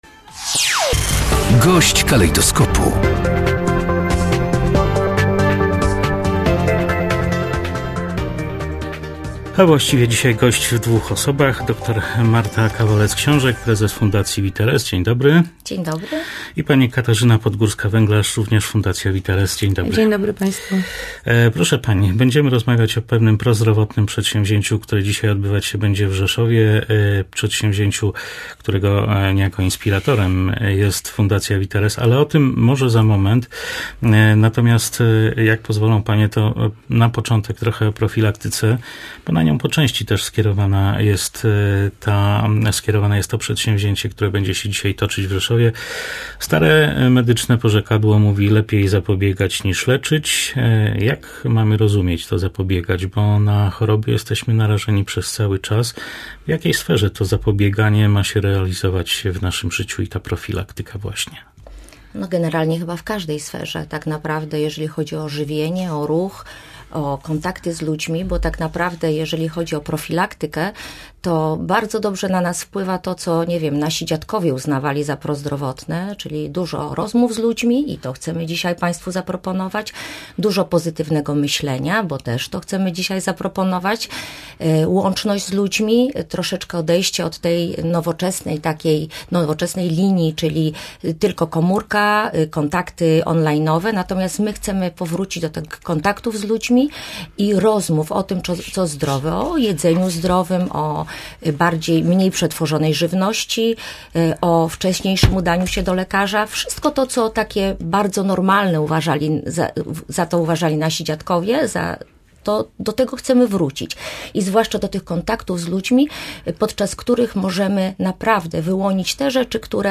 Gość dnia • Pomiary ciśnienia, tętna, cukru i darmowe loterie w których można wygrać badania laboratoryjne.